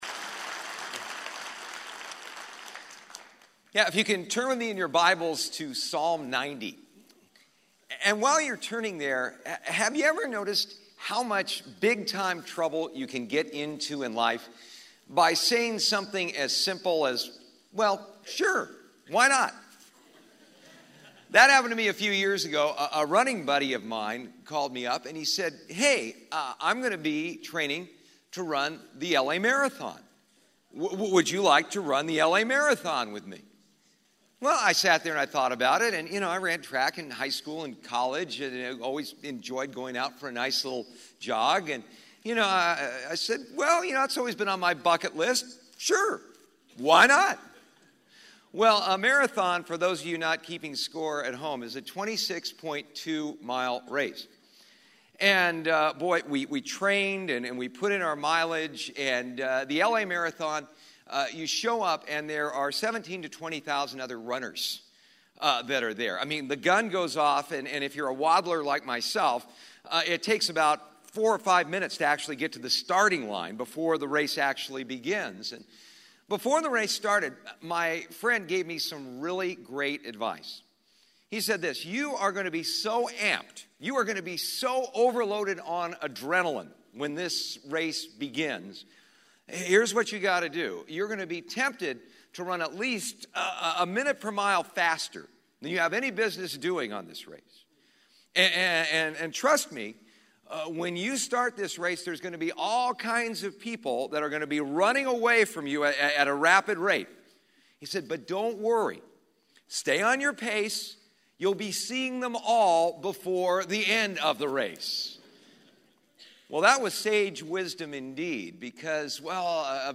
at the 2017 SW Pastors and Leaders Conference